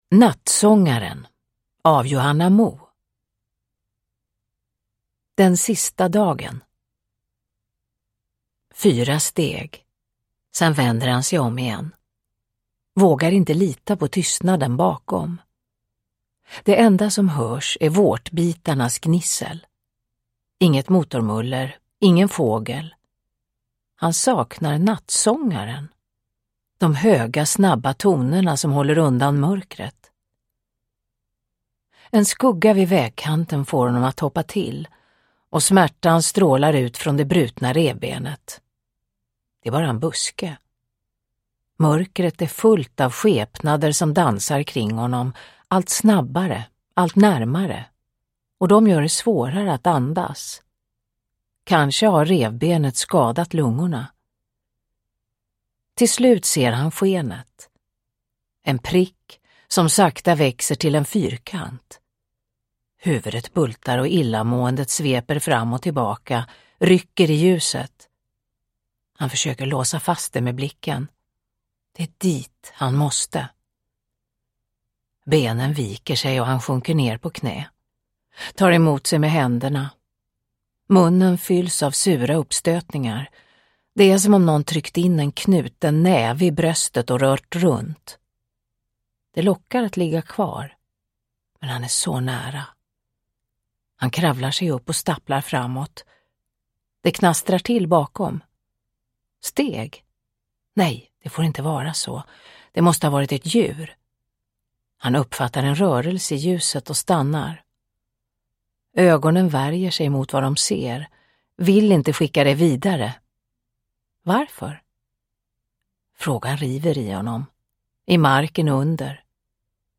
Nattsångaren – Ljudbok – Laddas ner
Uppläsare: Marie Richardson